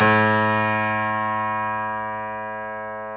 Index of /90_sSampleCDs/Club-50 - Foundations Roland/PNO_xTack Piano/PNO_xTack Pno 1M